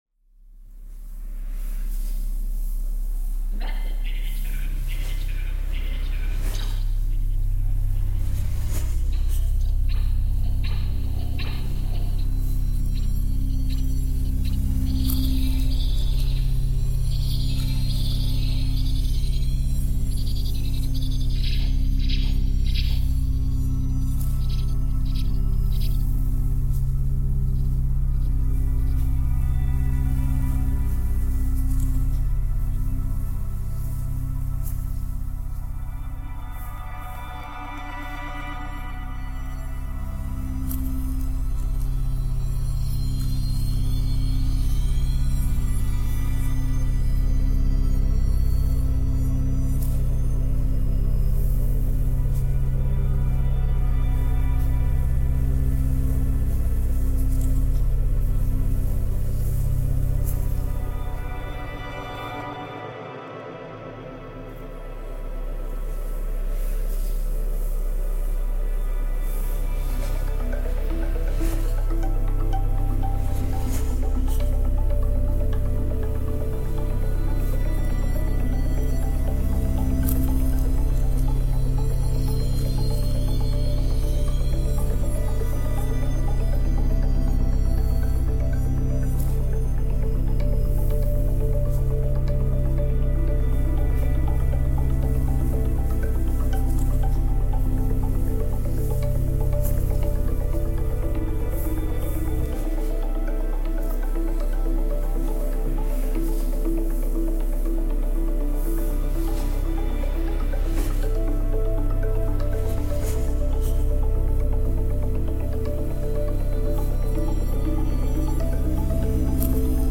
The field recording was surprisingly interesting when played backwards, add to that some musical bits and pieces, plus Psalm 20 read in Icelandic (appropriate to the location - thank you, Freesound) and a soupçon of Method (?) acting (thanks again, Freesound) - and there you have it.